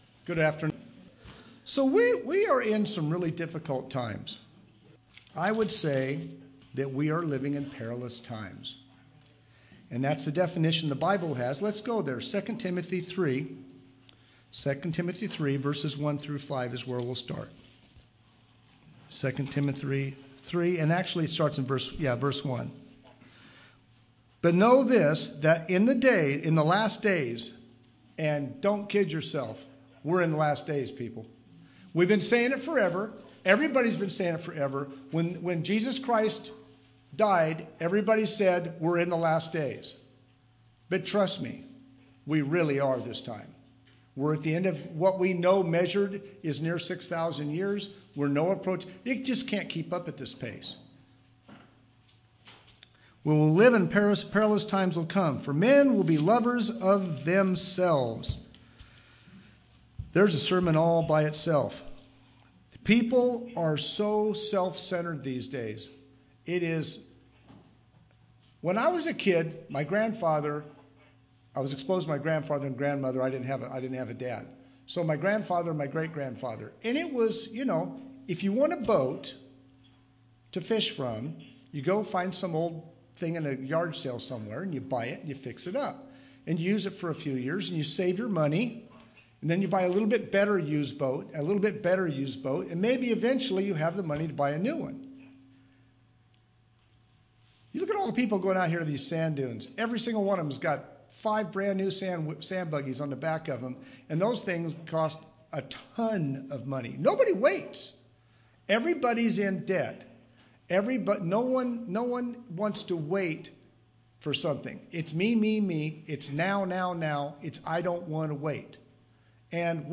Sermons
Given in Yuma, AZ